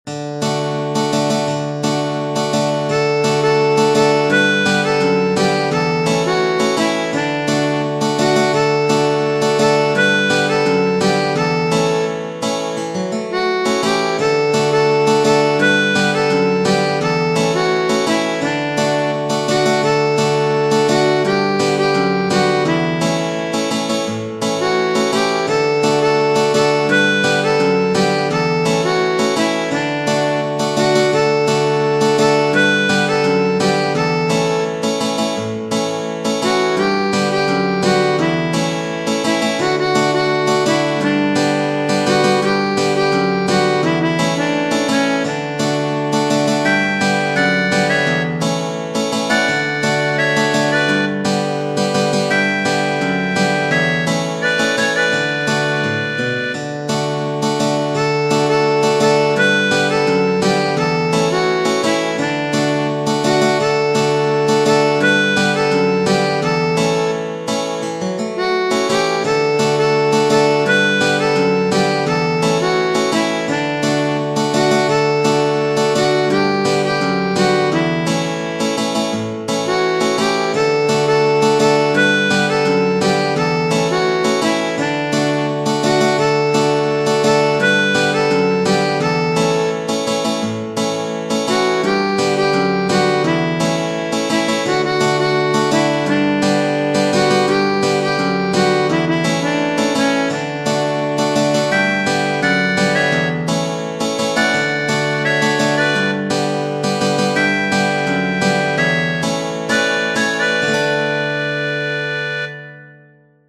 Genere: Folk